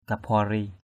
/ka-fʊa-ri:/ 1. (d.) tên nữ thủy thần = (cf. InraPatra) nom d’une divinité des Eaux. 2.